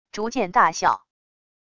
逐渐大笑wav音频